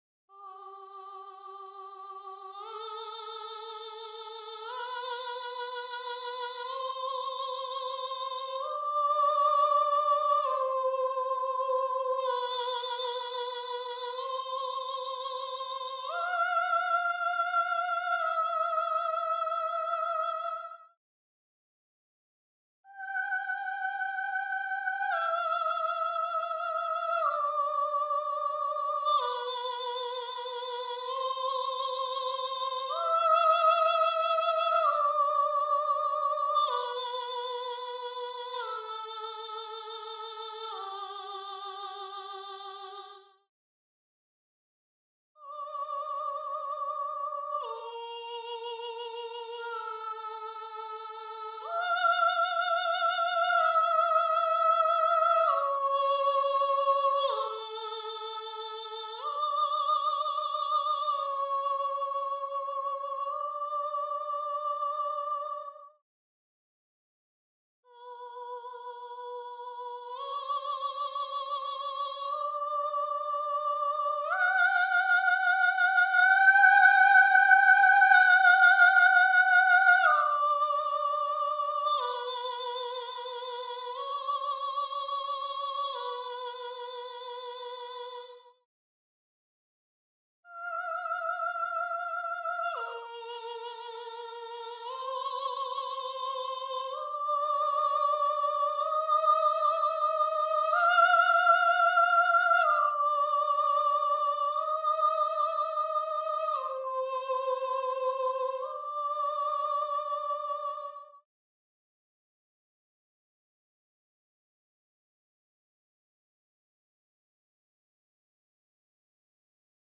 1. SOPRANO (Soprano/Soprano)
gallon-v3s1-20-Soprano_0.mp3